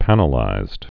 (pănə-līzd)